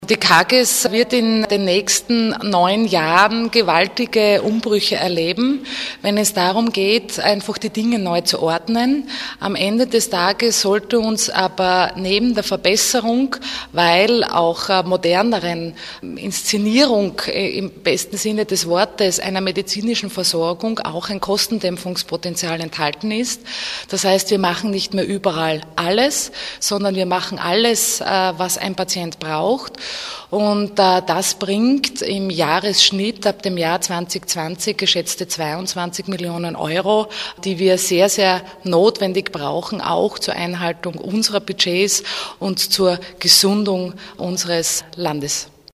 O-Ton: Strukturreform der steirischen Krankenanstalten präsentiert
Gesundheitslandesrätin Kristina Edlinger-Ploder: